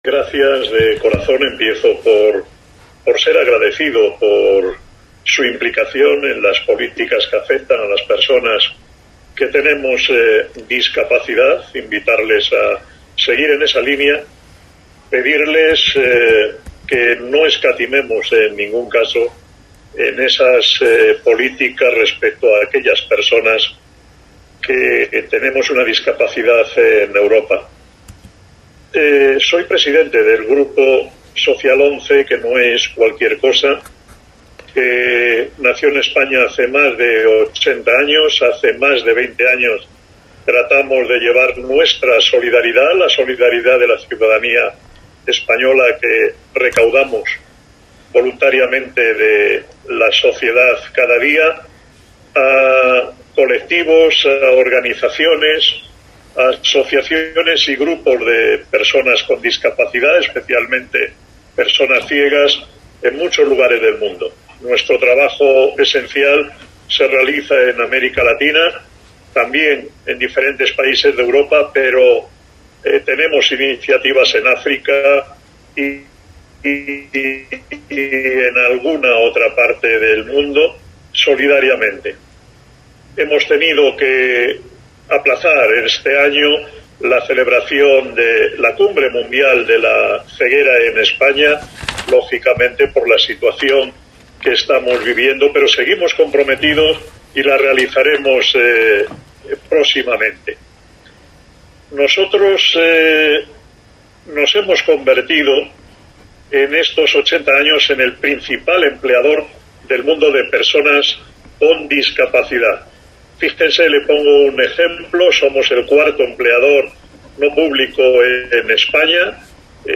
El presidente del Grupo Social ONCE participa en una reunión telemática sobre los derechos sociales de las personas con discapacidad y las repercusiones de la pandemia
Intervención de Carballeda en la Asamblea Parlamentaria del Consejo de Europa formato MP3 audio(8,38 MB)